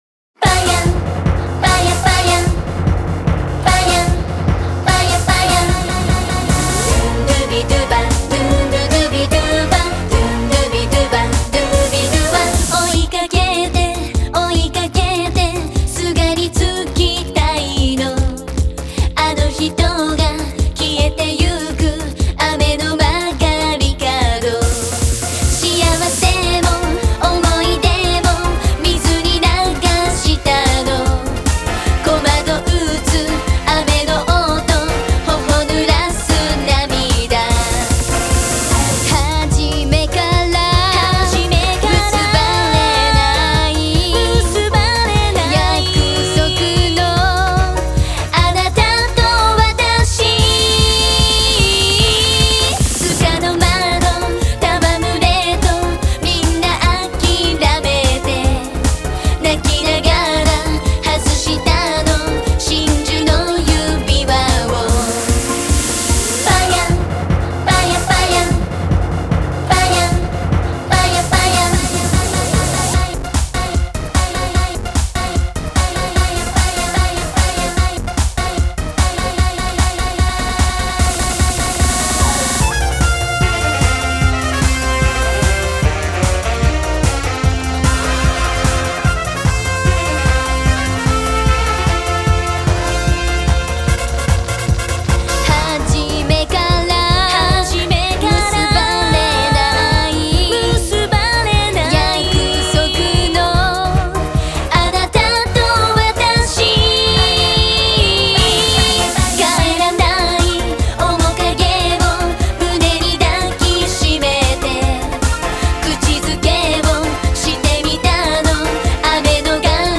аранжировка направлена на современный лад.